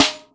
snare14.mp3